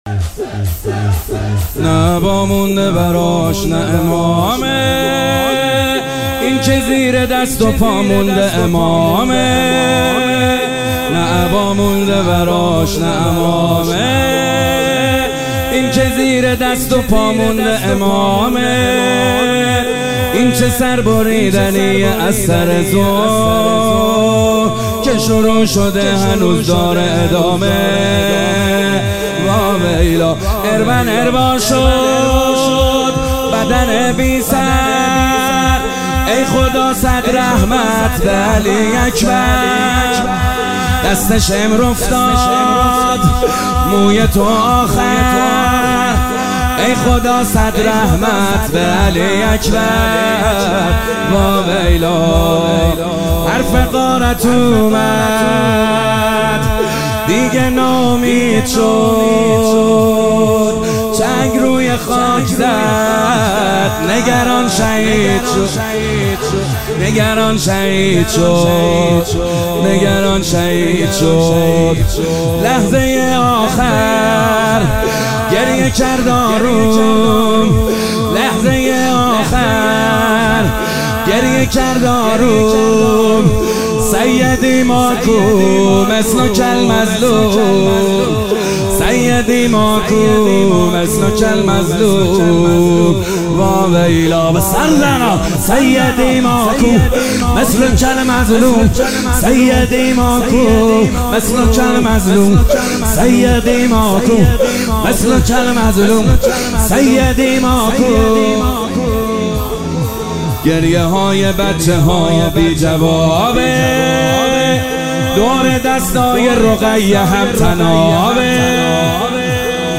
شب پنجم فاطمیه1402
شور - نه عبا مونده براش نه عمامه